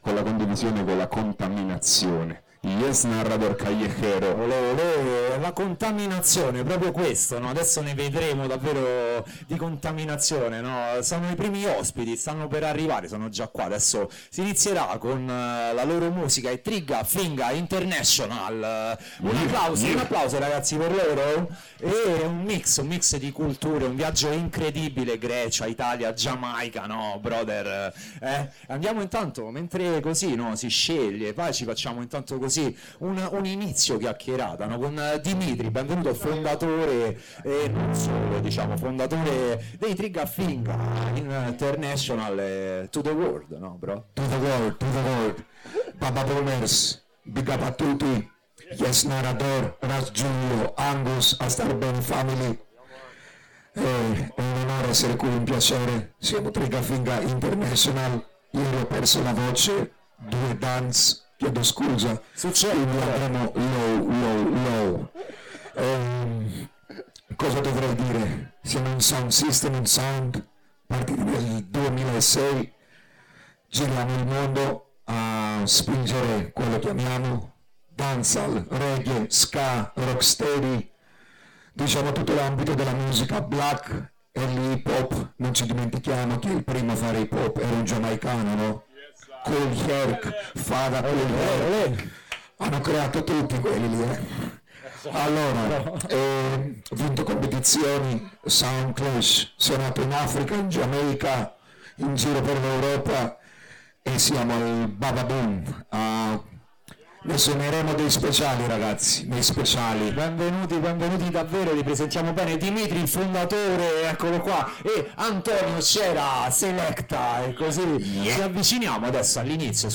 🔊 L’intervista, registrata live dalla Beach Yard, si snoda tra: